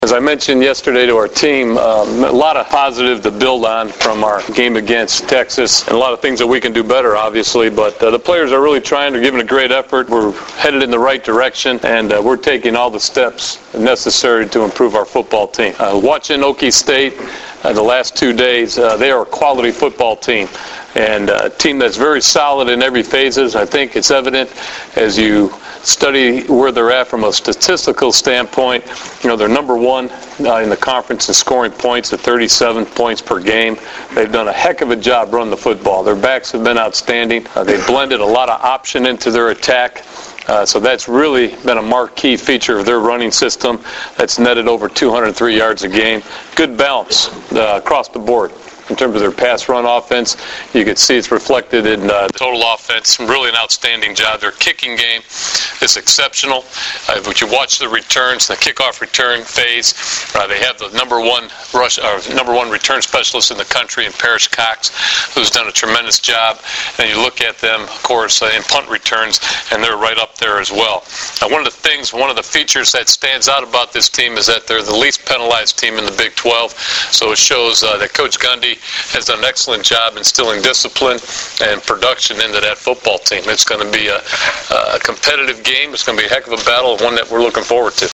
The following are audio links to press conference interview segments with Husker players and NU head coach Bill Callahan.
Head Coach Bill Callahan